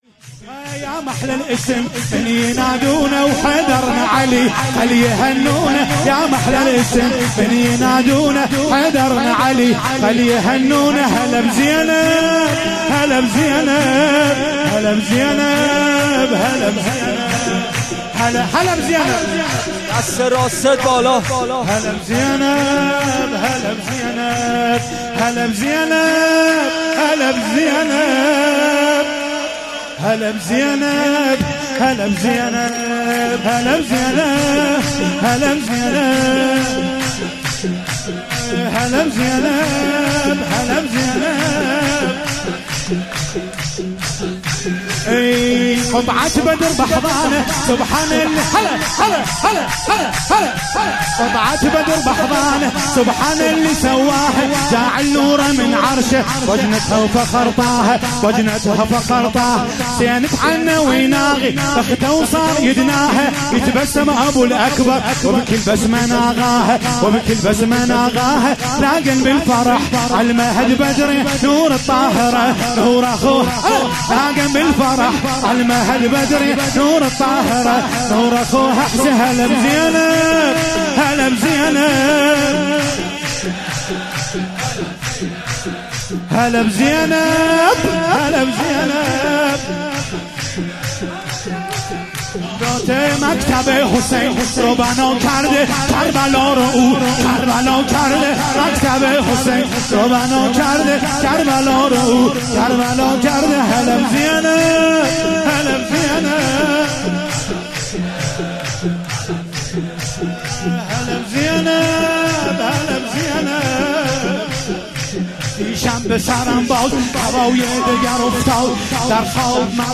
شور عربی سرود